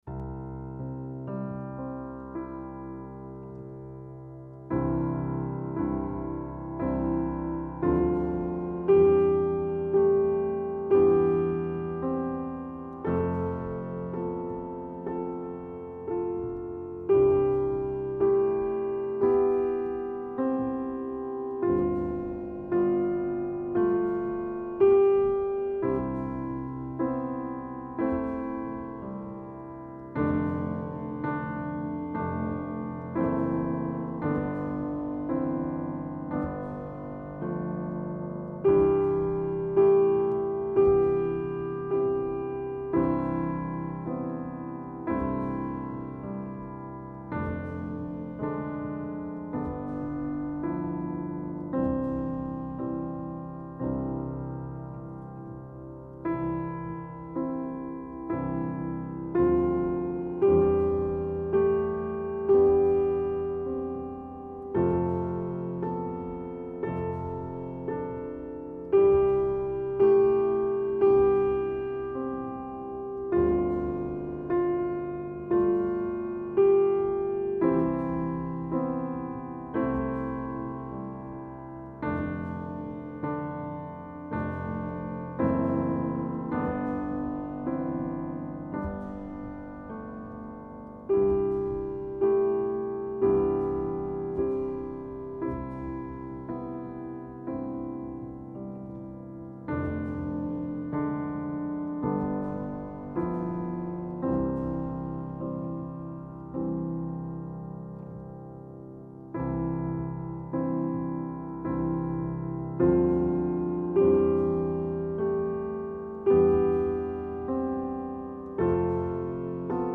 Begleitmelodie zu den rhythmischen Atemübungen …...